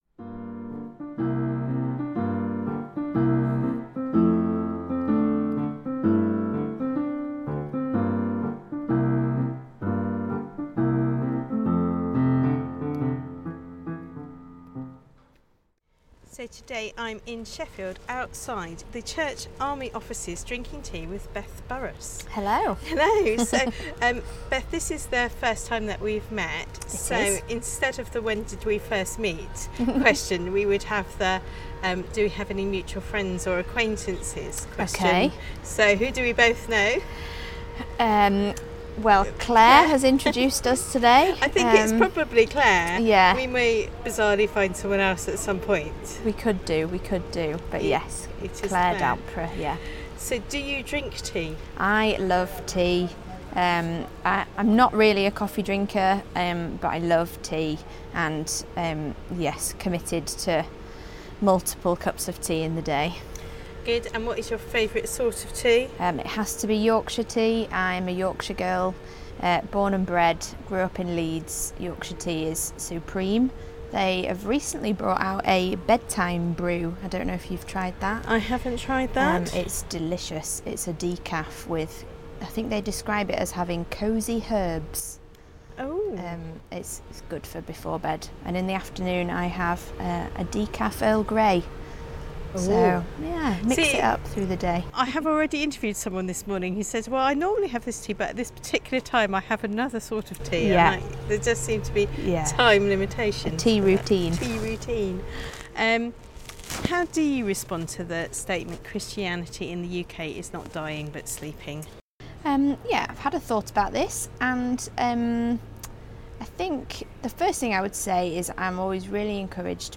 Bit of road resurfacing also creeps in...